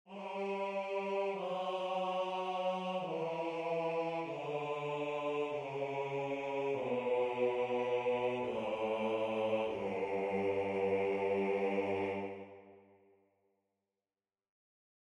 Key written in: G Major
Type: Barbershop
Each recording below is single part only.